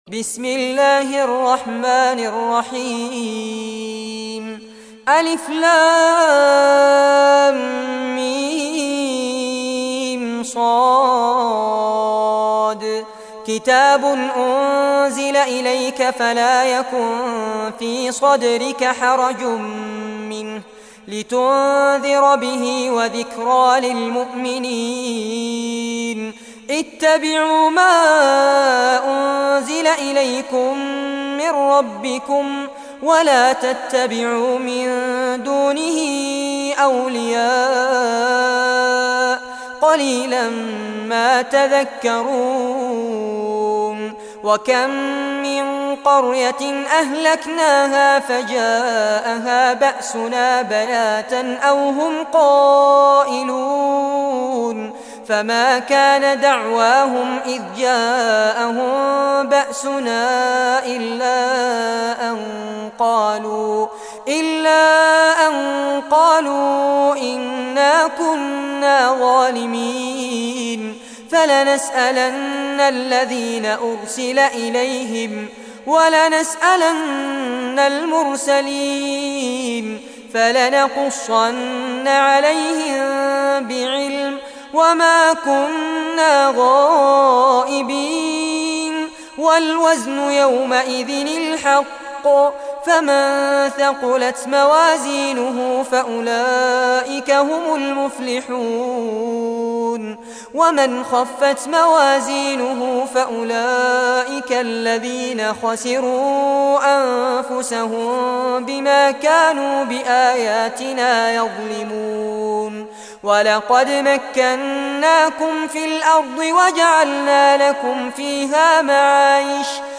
Surah Al-Araf Recitation by Sheikh Fares Abbad
Surah Al-Araf, listen or play online mp3 tilawat / recitation in Arabic in the beautiful voice of Sheikh Fares Abbad.